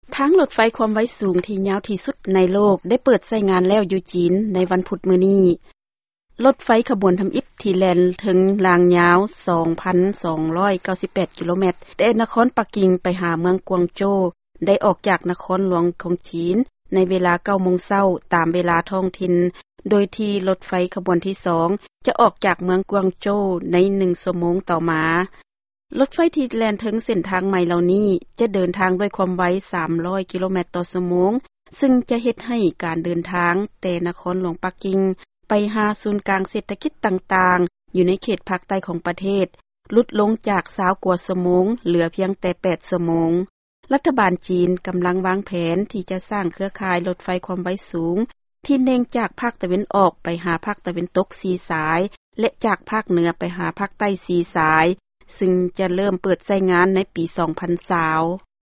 ຟັງຂ່າວລົດໄຟຄວາມໄວສູງຂອງຈີນ